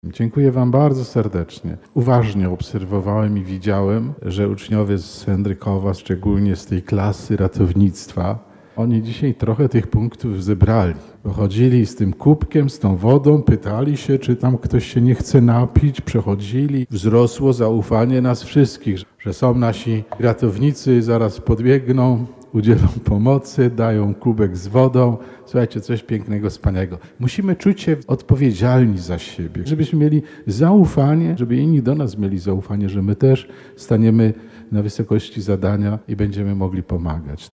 Mszą Św. w Katedrze Wrocławskiej rozpoczęła się inauguracja roku szkolnego 2025/2026 dla placówek katolickich Archidiecezji Wrocławskiej.
Metropolita Wrocławski także skierował słowo do dzieci i młodzieży rozpoczynających nowy rok szkolny.